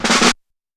just blaze snareroll6.WAV